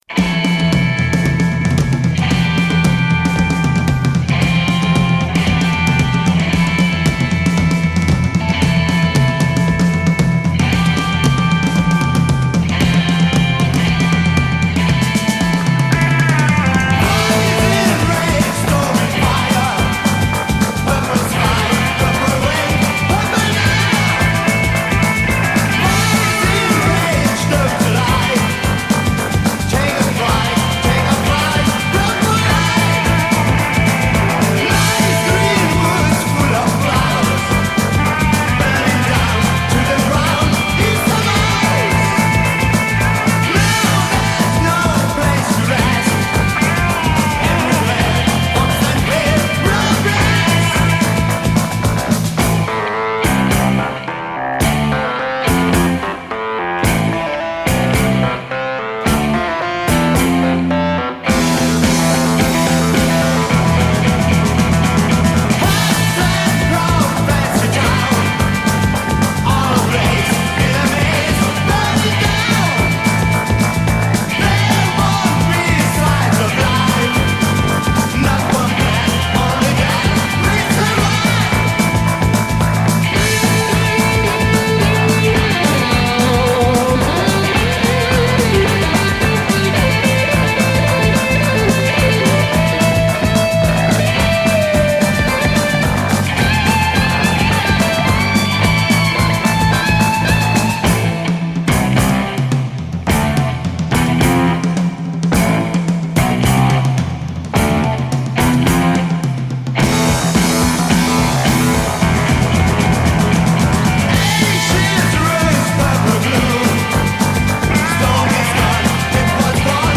Hard Rock, Progressive Rock